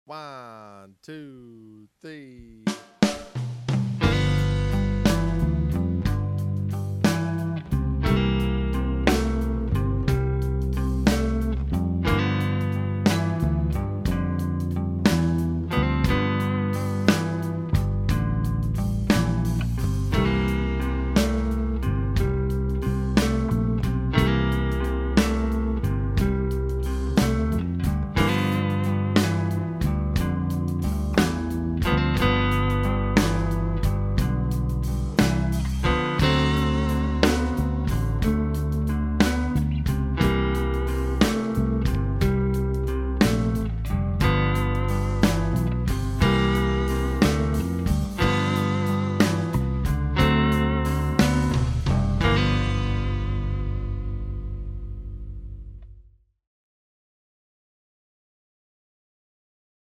12-bar Blues in G - Here's a great-sounding professional recording to jam with.  Use G minor pentatonic or G blues scale.
12 Bar Blues in G.mp3